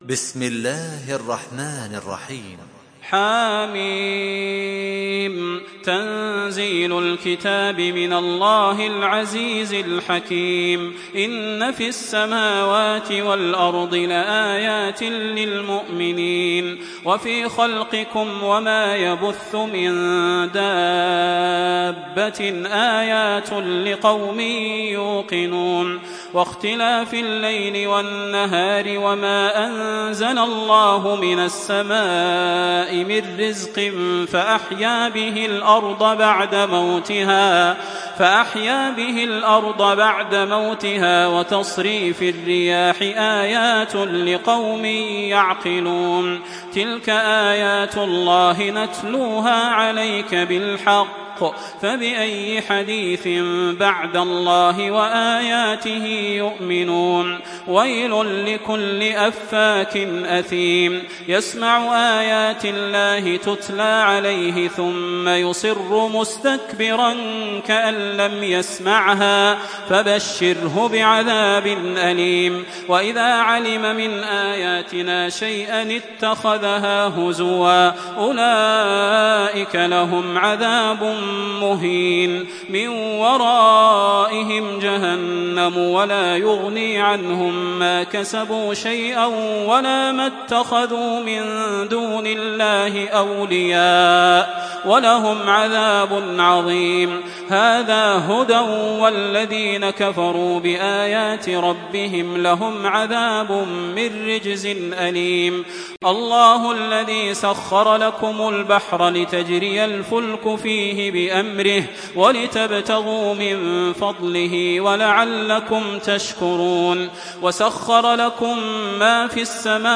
Makkah Taraweeh 1427
Murattal